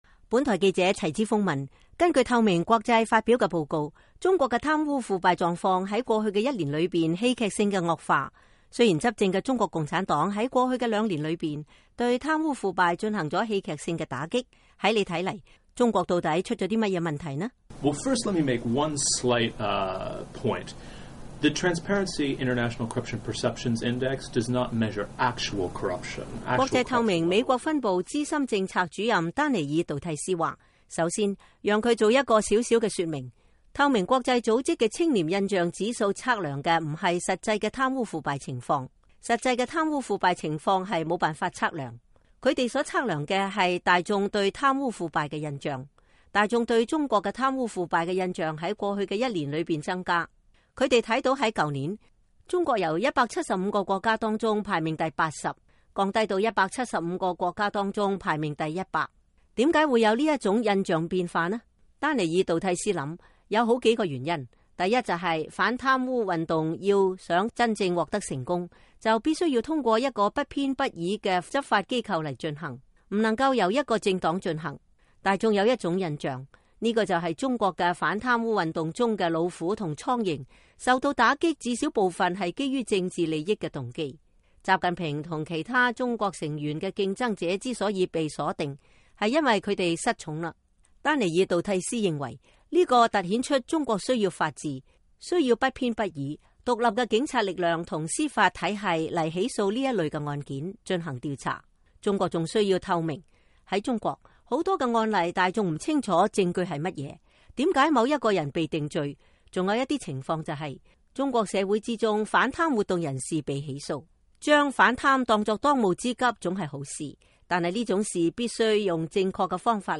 下面是問答對話全文。